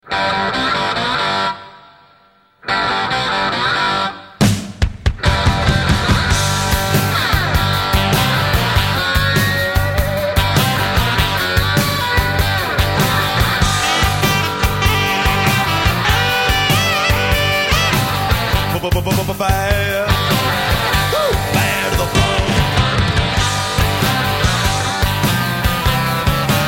• Качество: 128, Stereo
бас-гитара